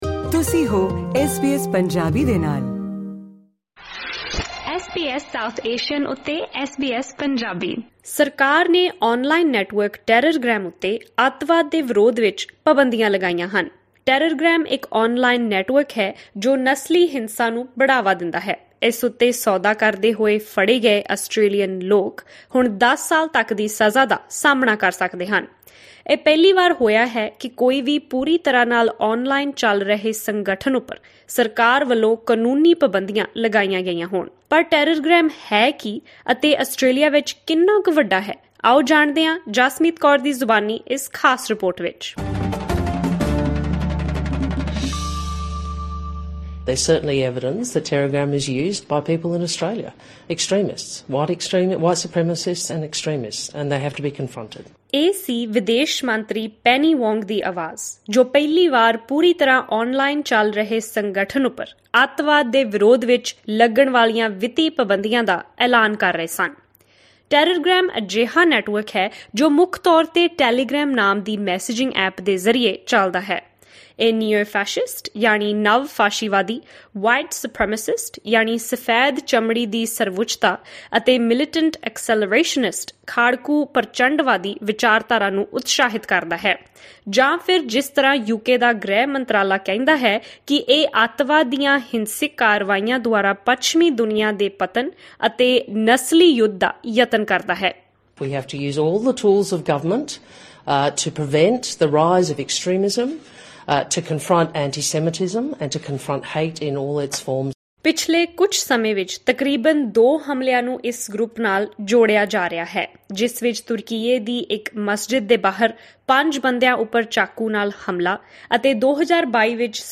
'ਟੈਰਰਗਰੈਮ' ਇੱਕ ਆਨਲਾਈਨ ਨੈੱਟਵਰਕ ਹੈ ਜੋ ਨਸਲੀ ਹਿੰਸਾ ਨੂੰ ਉਤਸ਼ਾਹਿਤ ਕਰਦਾ ਹੈ। ਇਹ ਐਪ ਹੈ ਕੀ ਅਤੇ ਇਸ ਨੂੰ ਵਰਤਣ ਵਾਲੇ ਕਿੰਨਾ ਕੁ ਨੁਕਸਾਨ ਕਰ ਚੁੱਕੇ ਹਨ ਜਾਨਣ ਲਈ ਸੁਣੋ ਇਹ ਖਾਸ ਰਿਪੋਰਟ।